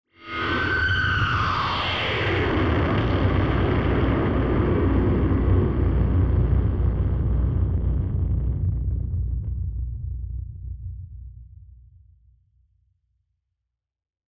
BF_DrumBombDrop-07.wav